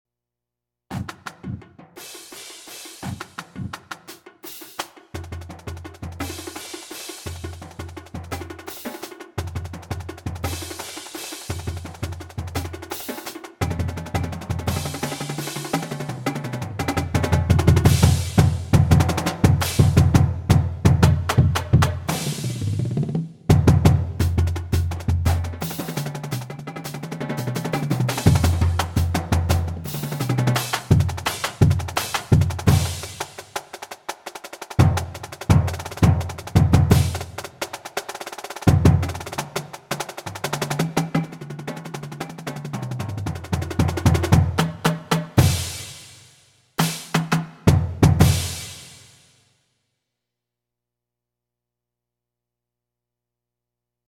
Voicing: Marching Percussion